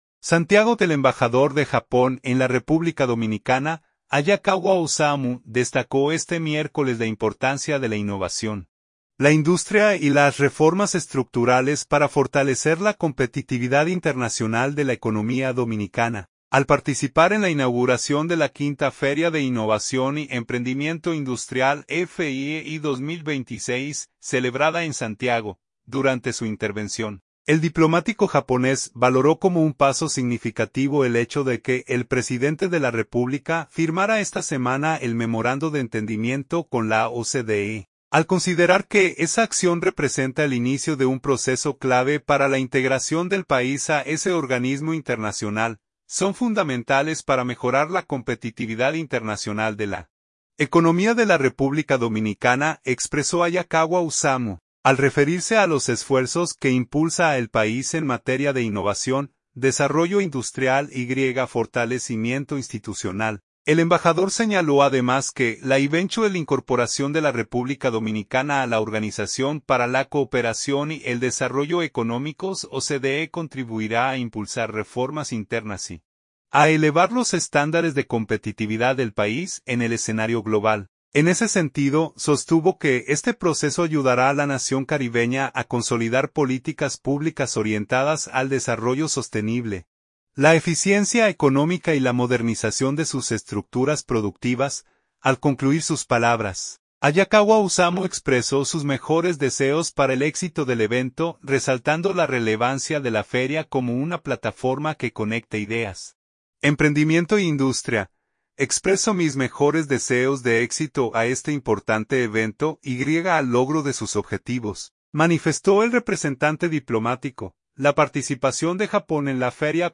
Santiago.- El embajador de Japón en la República Dominicana, Hayakawa Osamu, destacó este miércoles la importancia de la innovación, la industria y las reformas estructurales para fortalecer la competitividad internacional de la economía dominicana, al participar en la inauguración de la quinta Feria de Innovación y Emprendimiento Industrial (FIEI 2026), celebrada en Santiago.